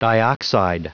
Prononciation du mot dioxide en anglais (fichier audio)
Prononciation du mot : dioxide